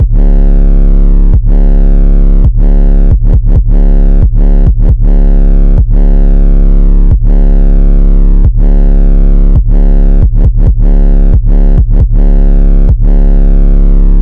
胖大男孩裤子808
描述：胖胖的808
Tag: 135 bpm Trap Loops Bass Wobble Loops 2.39 MB wav Key : C FL Studio